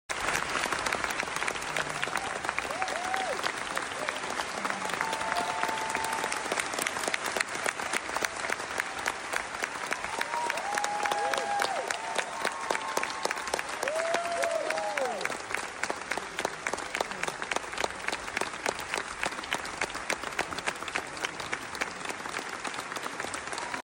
Sigourney Weaver takes a bow alongside the cast of Jamie Lloyd’s The Tempest